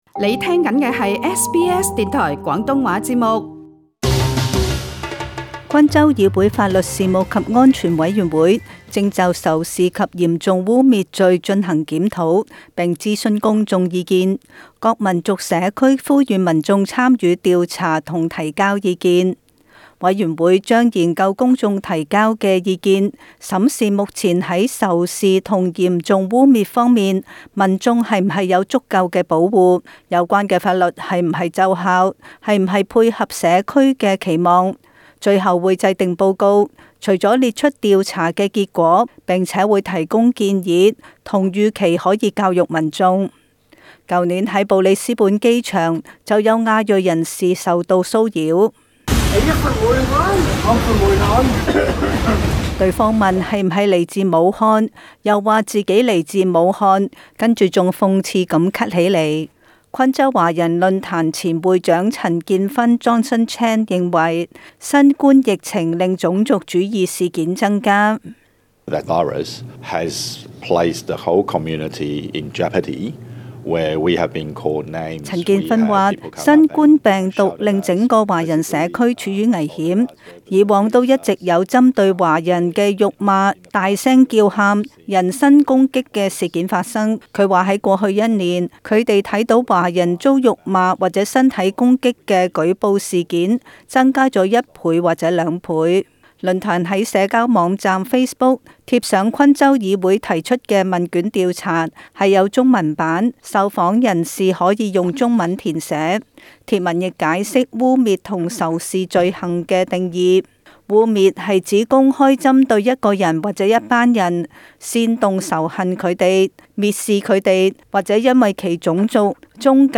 时事报道